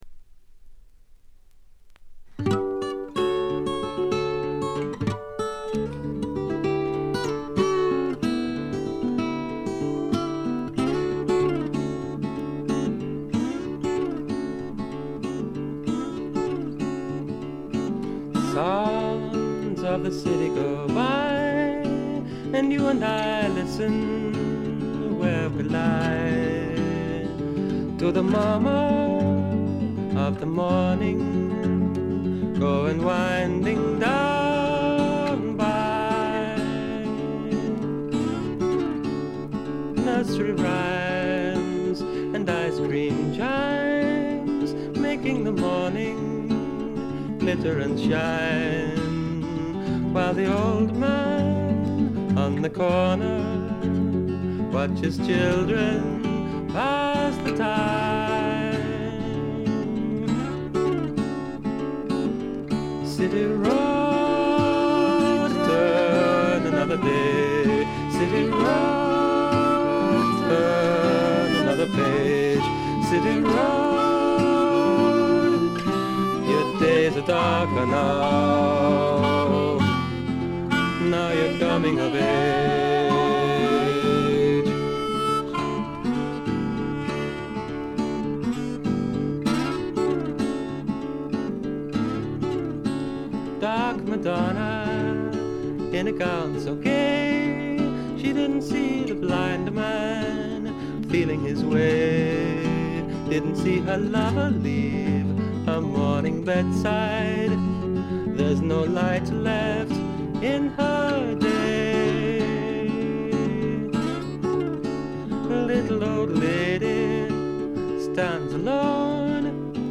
両面とも冒頭軽いチリプチ。
英国流ドリーミー・フォークとしても聴ける名盤です。
ほのかにただようアシッド感覚がいいですね。
試聴曲は現品からの取り込み音源です。
Guitar [Second]
Harmonica